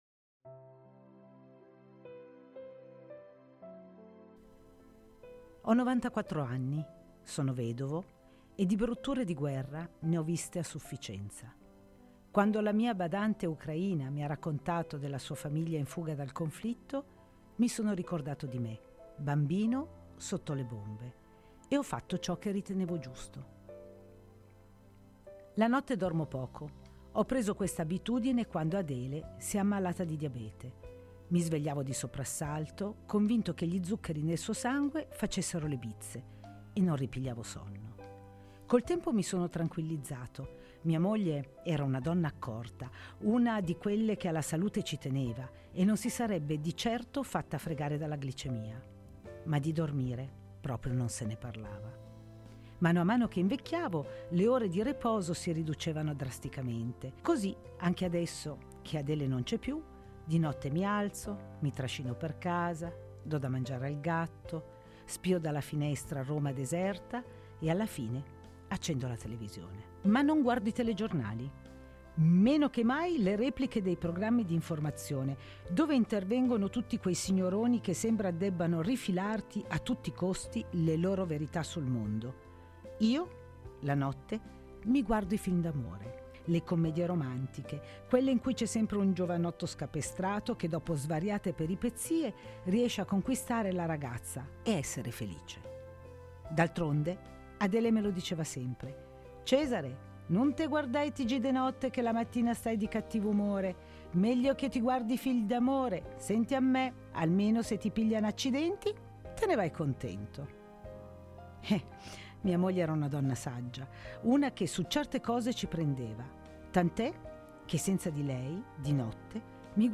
Ascolta la storia in Podcast.